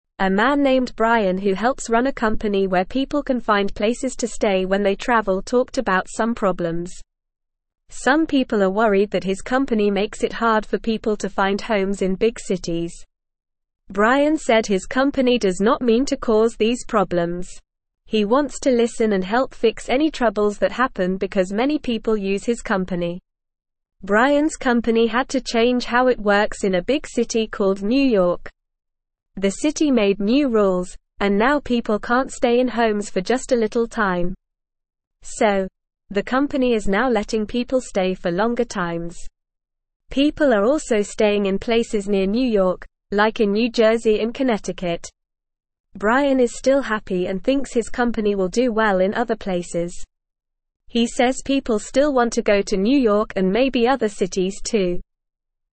Normal
English-Newsroom-Beginner-NORMAL-Reading-Brian-Helps-People-Find-Places-to-Stay-When-They-Travel.mp3